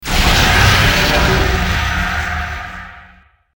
monster_atk_skill.mp3